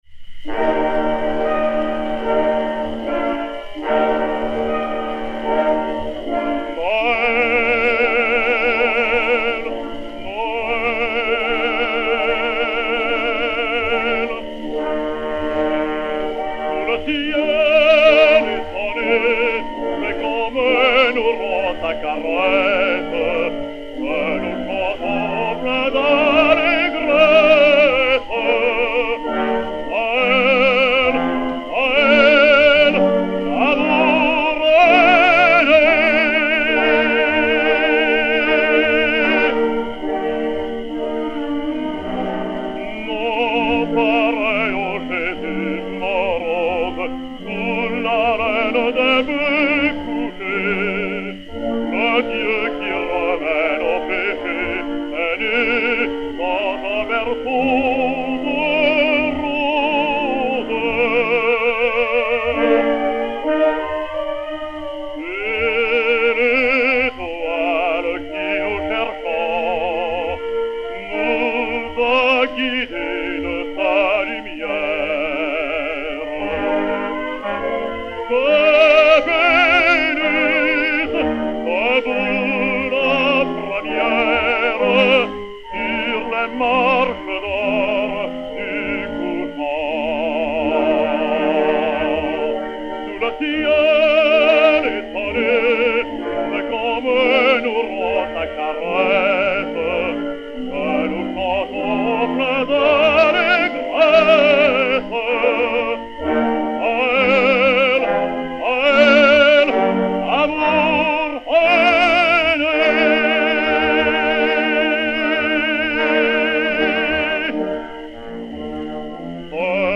Mélodie
basse, avec Orchestre